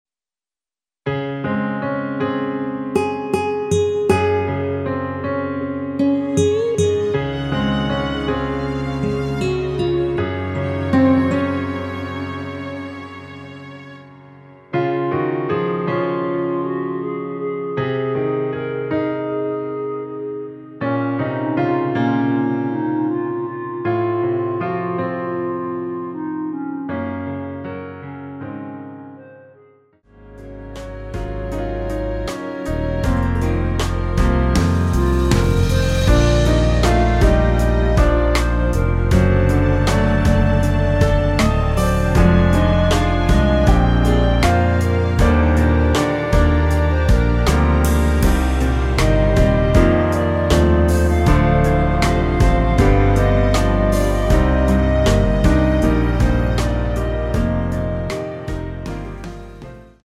원키에서(-7)내린 멜로디 포함된 MR입니다.(미리듣기 참조)
Db
앞부분30초, 뒷부분30초씩 편집해서 올려 드리고 있습니다.
(멜로디 MR)은 가이드 멜로디가 포함된 MR 입니다.